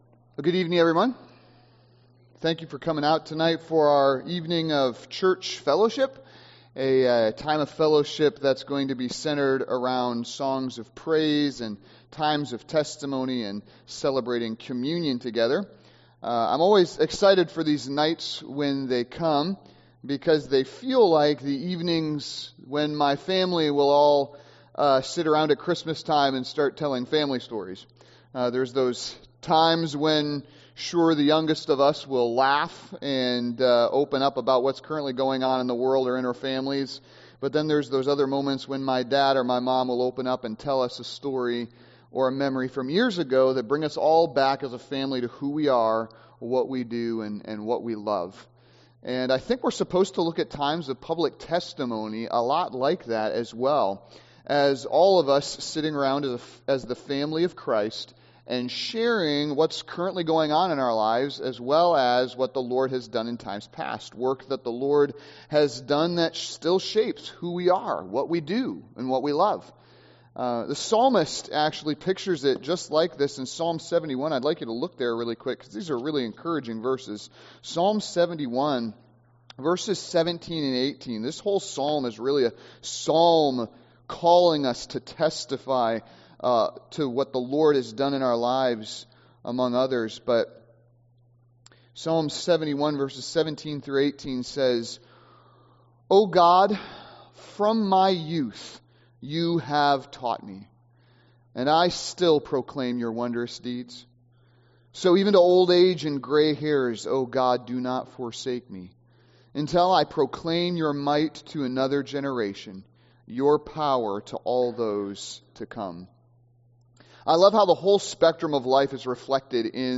Praise & Testimony Service